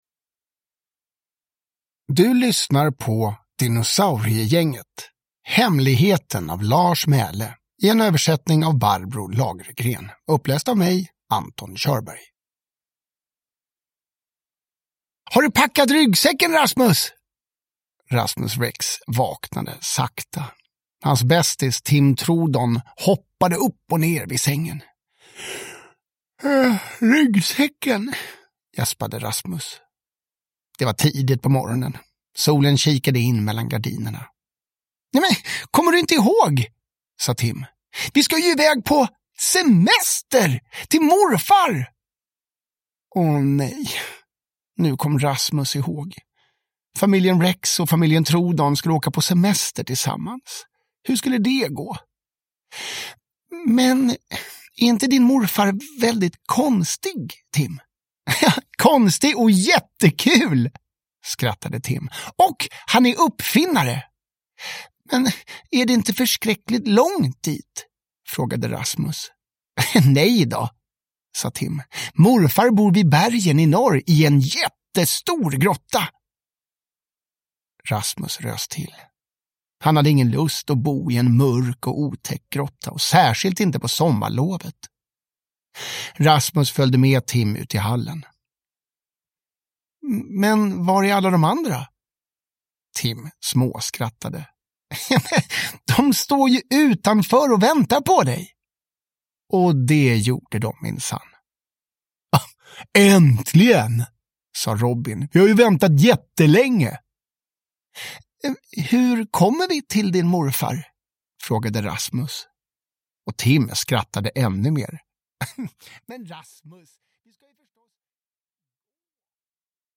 Hemligheten (ljudbok) av Lars Mæhle